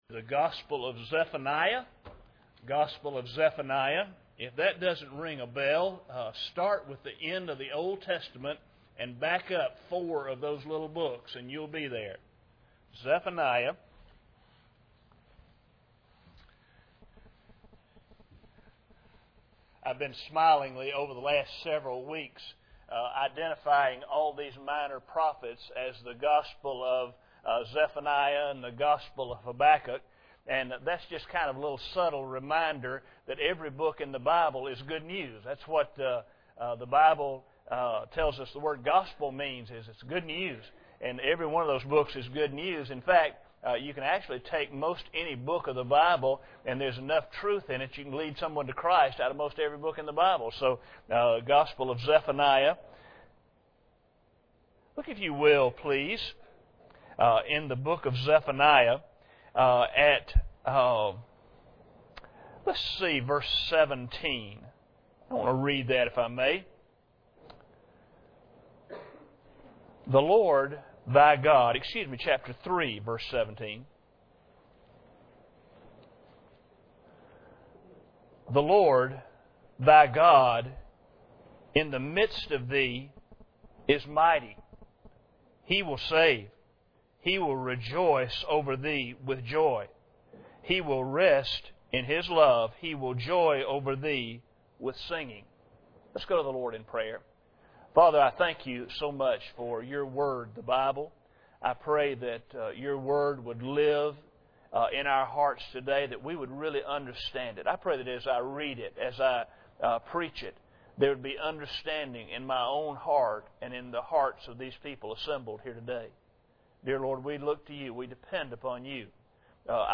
General Service Type: Sunday Morning Preacher